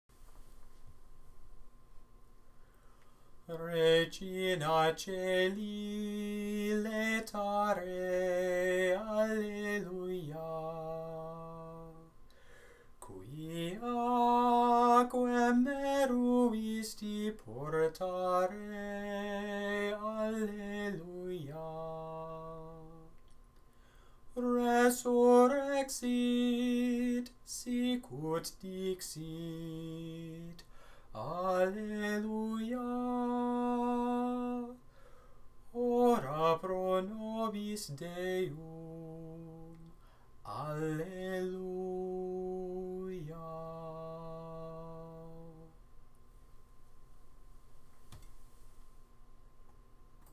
Gregorian, Catholic Chant Regina Caeli
Catholic Chant
Use: Compline hymn during Paschal Time